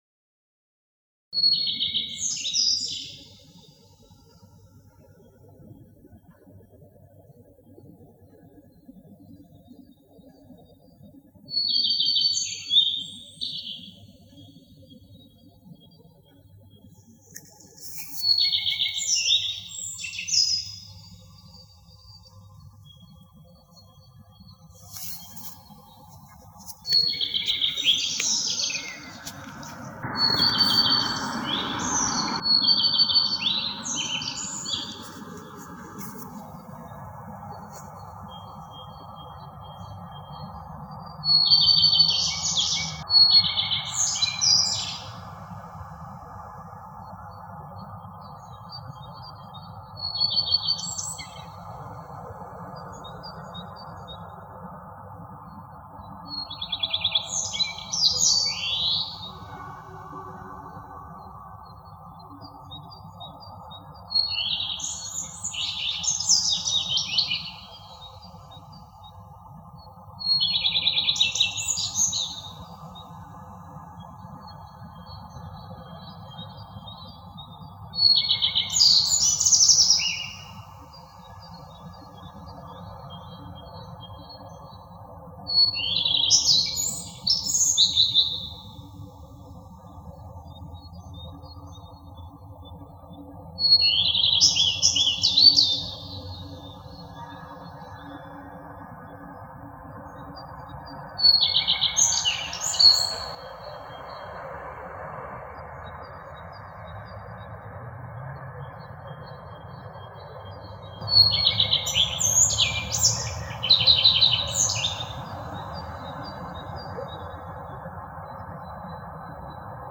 Раннее утро (5 часов утра). Большой город ещё спит. Тишина нарушается редкими авто и трелями варакушек.
Удалось записать варакушку - "сибирского соловья". Всю ночь и утро выдают свои весёлые весенние трели. Немного мешали  редкие авто, которые проезжали по недалёкой от дома улице.